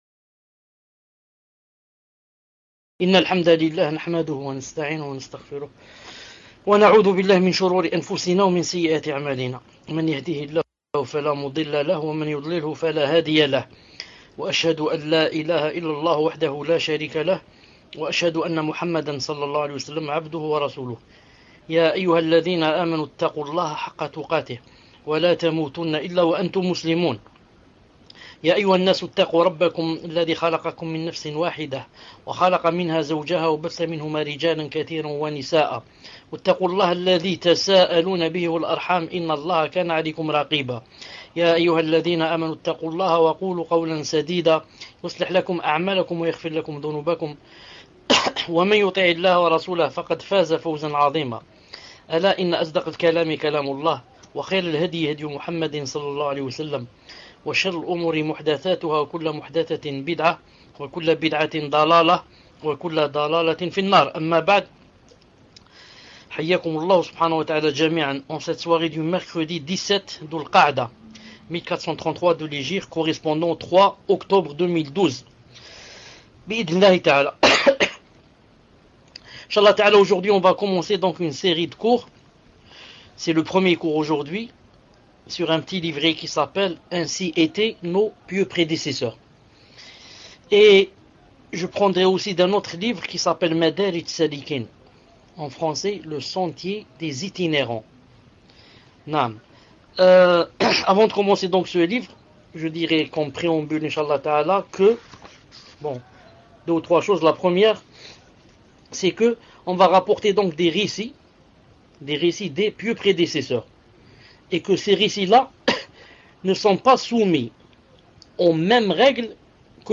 Mercredi 17 Dhul-Qa'dah 1433 - 03 Octobre 2012 | 1er cours - Durée : 2h 05 min